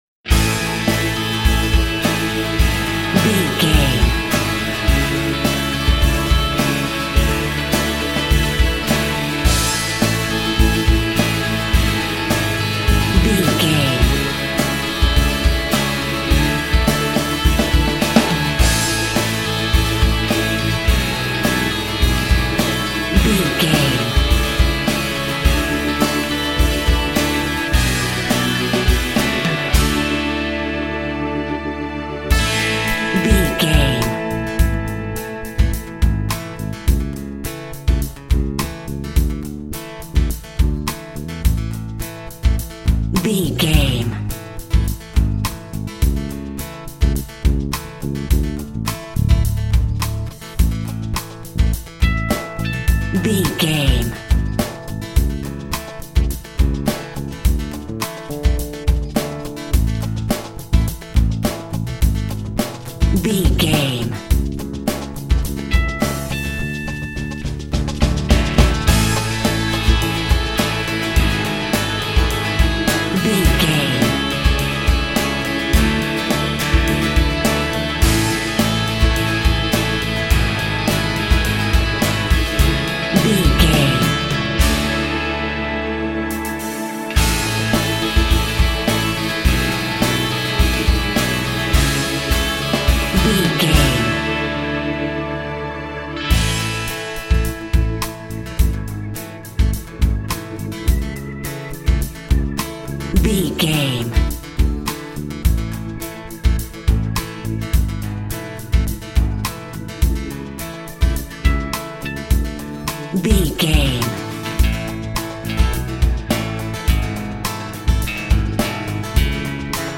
Ionian/Major
distortion
hard rock
Instrumental rock
drums
bass guitar
electric guitar
piano
hammond organ